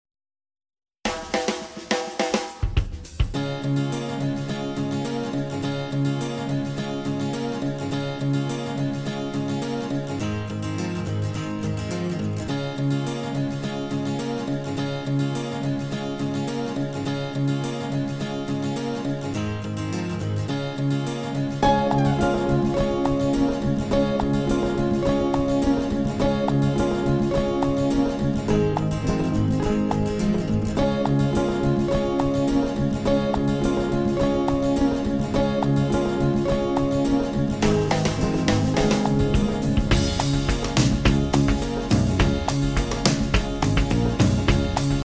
Instrumental